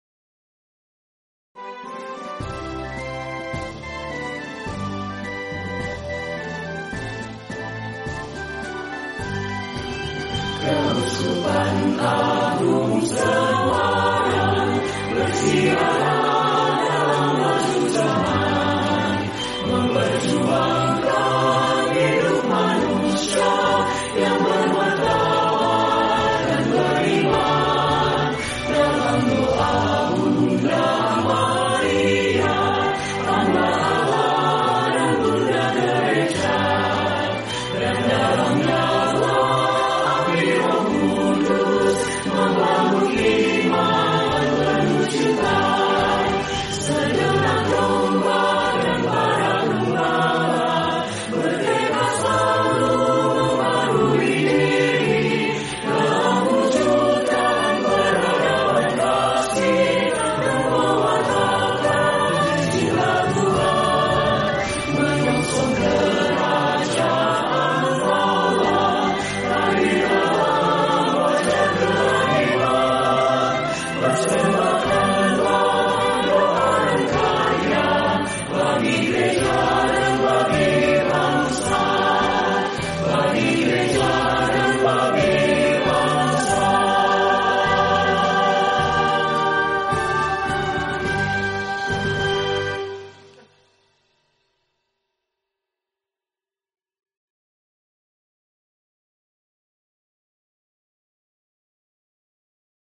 MARS-KEUSKUPAN-AGUNG-SEMARANG-Virtual-Choir-Umat-KAS.mp3